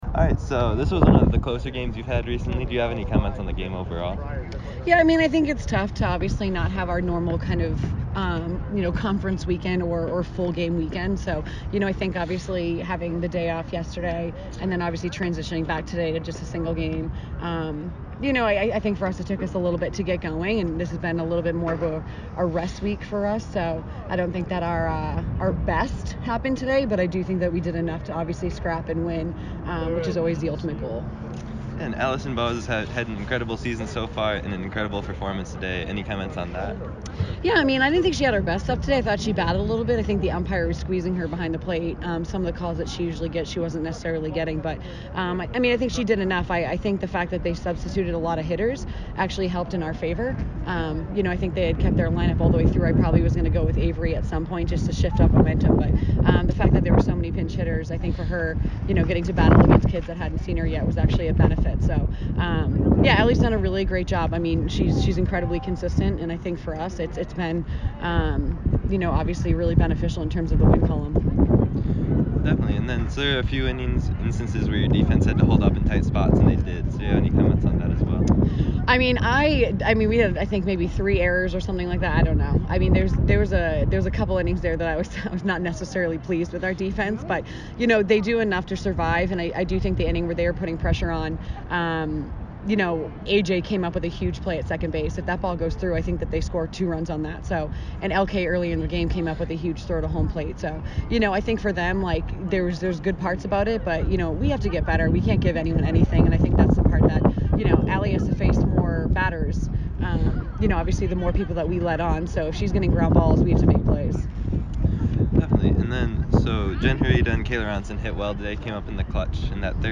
Providence Softball Postgame Interview